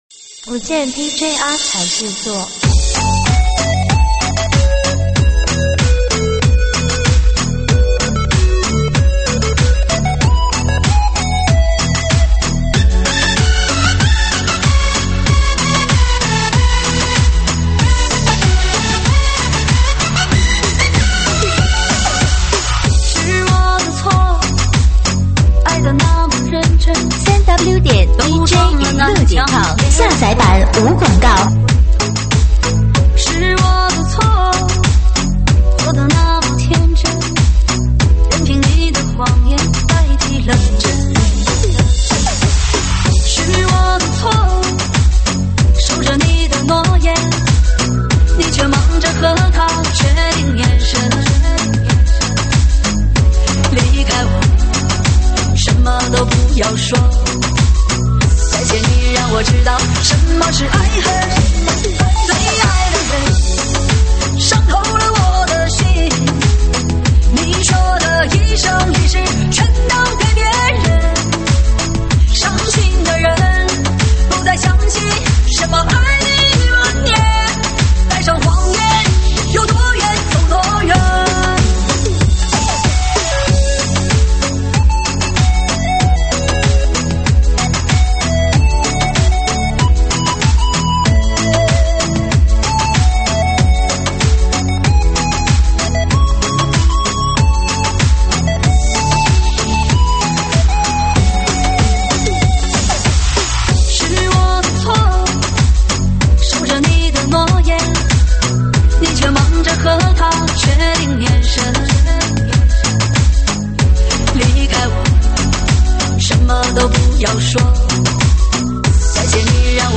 舞曲类别：吉特巴